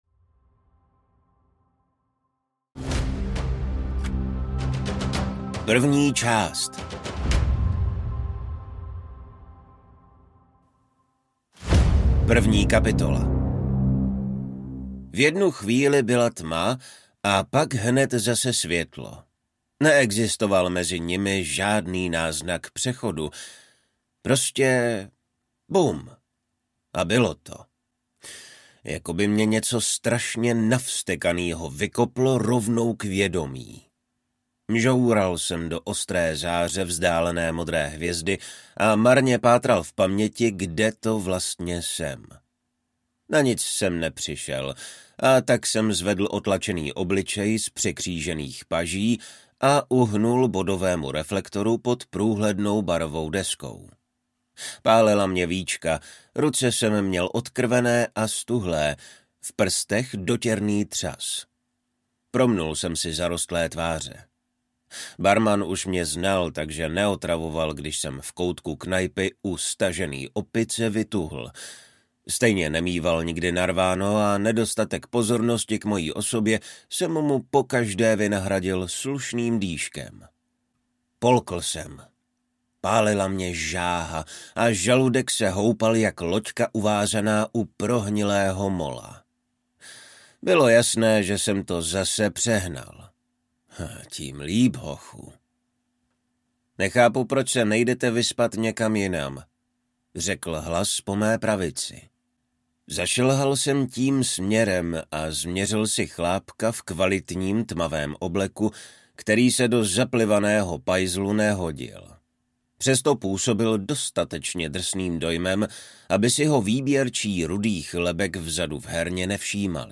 Hvězda 01 audiokniha
Ukázka z knihy